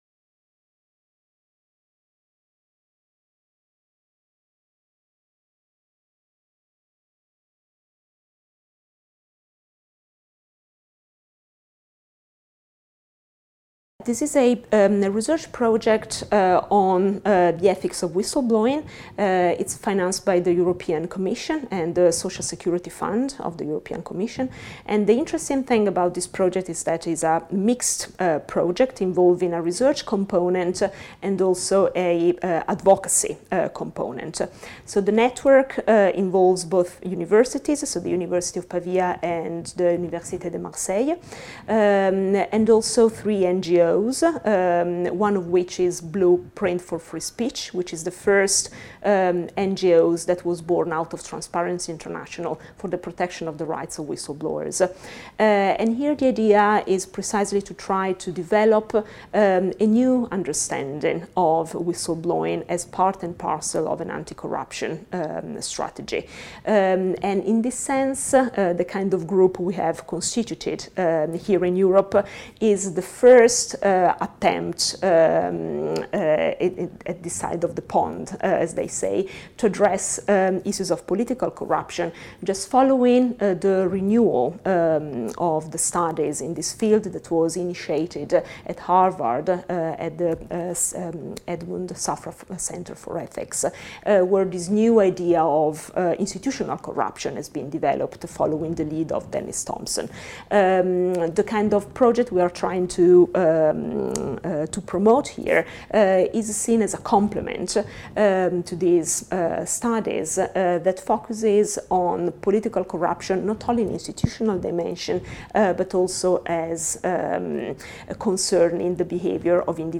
The relational dimension of political corruption : Interview